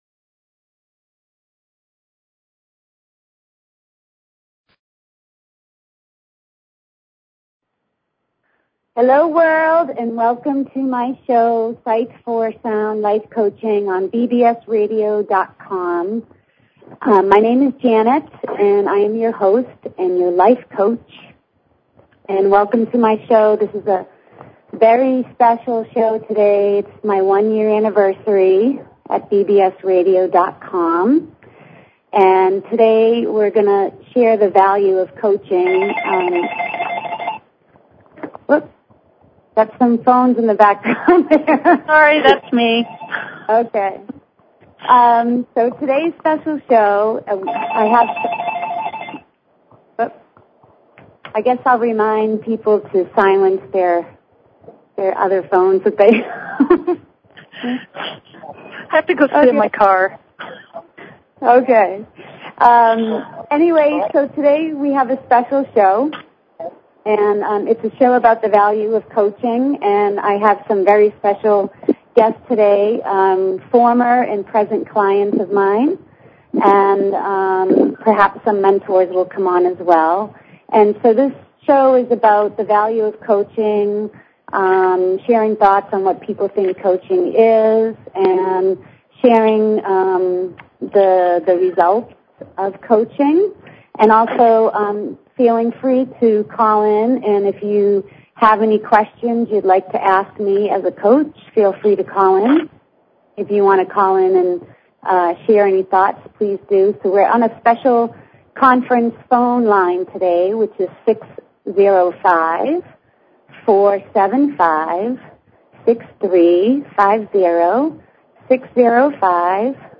Talk Show Episode, Audio Podcast, Sight_for_Sound and Courtesy of BBS Radio on , show guests , about , categorized as
The Value of Coaching with special guests, my clients.
She encourages you to call in and ask questions or share thoughts!!!